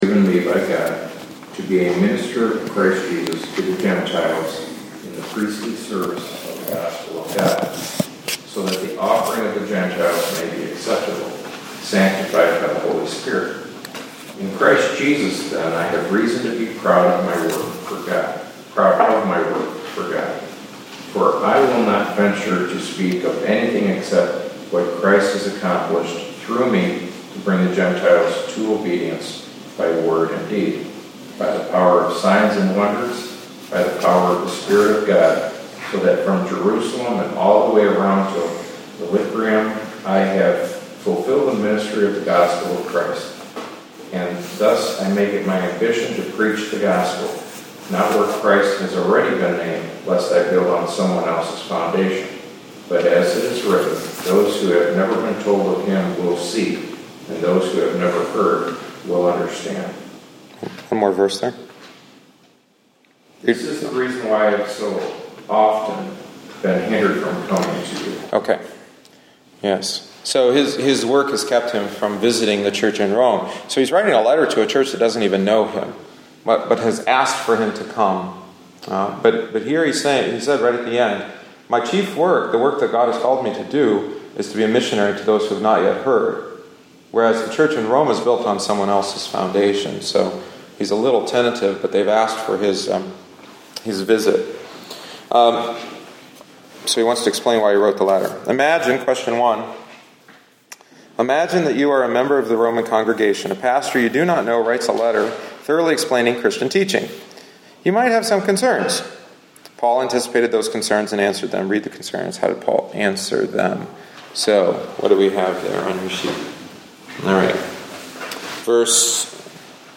The following is the thirty-sixth week’s lesson. At this point Paul begins to bring his letter to a close.